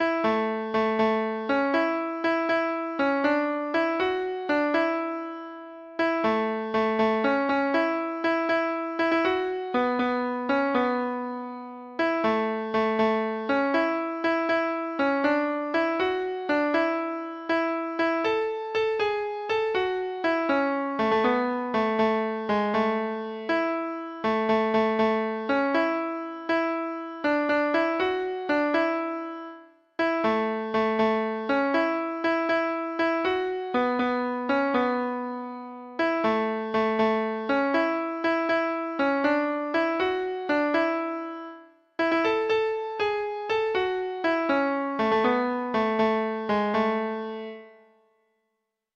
Traditional Trad. Flunky Jim Treble Clef Instrument version
Folk Songs from 'Digital Tradition' Letter F Flunky Jim
Traditional (View more Traditional Treble Clef Instrument Music)